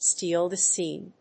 アクセントstéal the scéne